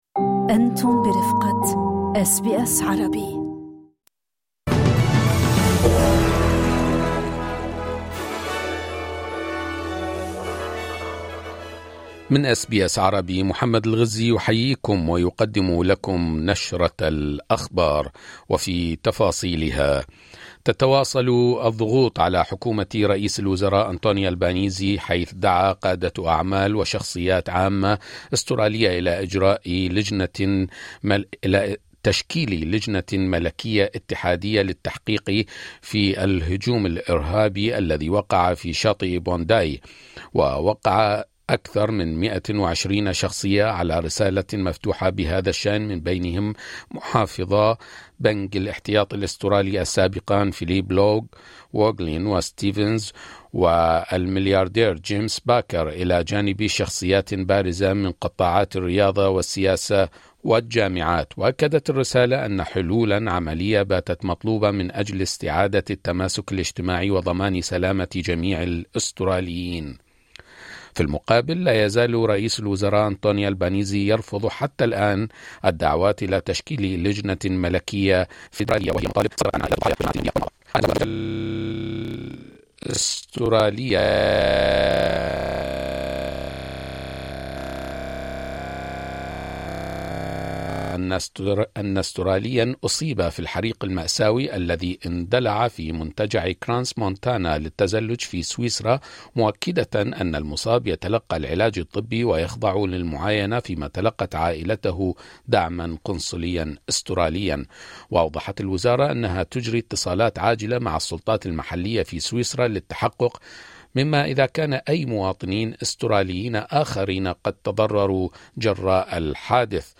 نشرة أخبار الظهيرة 02/01/2025